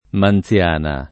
[ man ZL# na ]